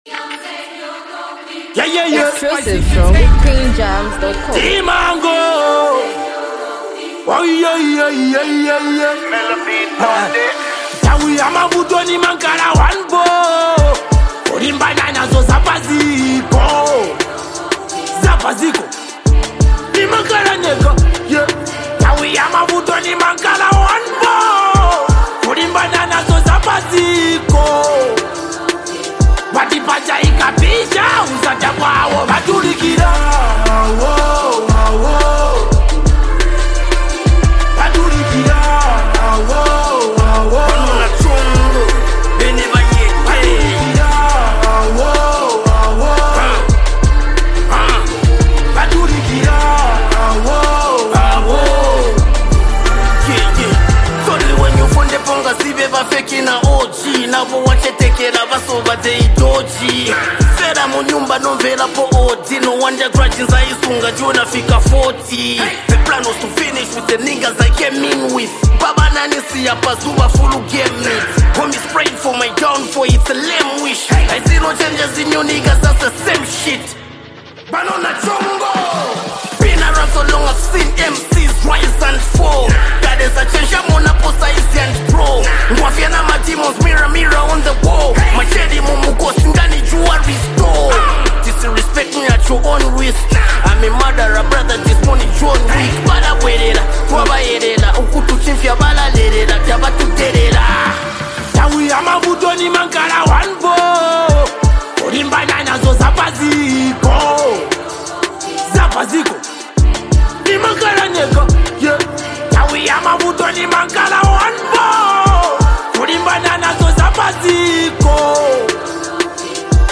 hype, street vibes, and a powerful hook
unique rap flow